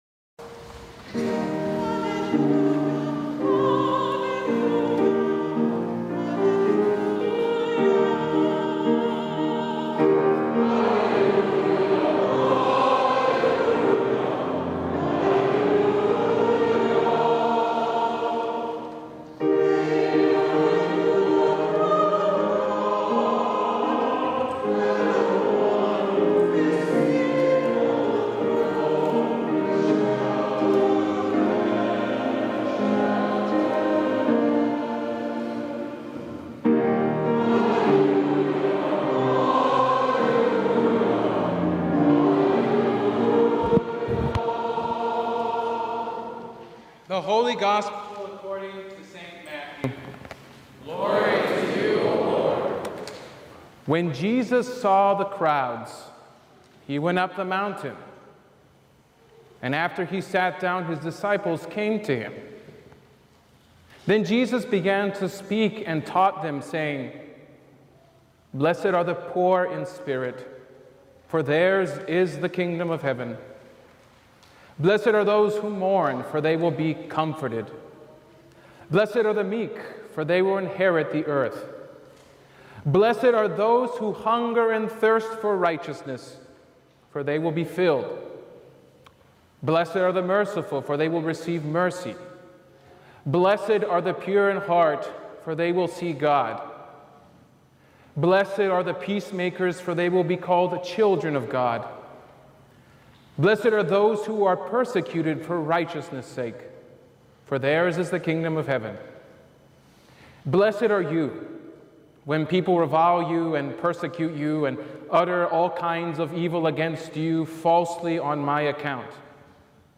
Sermons from Christ the King, Rice Village | Christ The King Lutheran Church